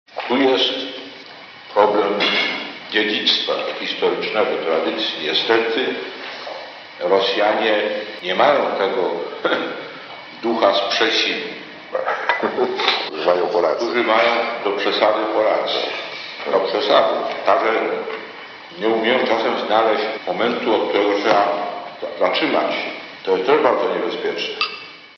Taśmy z rozmowami nagranymi w czasie kolacji, w prywatnych apartamentach Ojca Świętego Jana Pawła II w roku 1988 i 1992, przekazał dziennikarz i polityk Jaś Gawroński.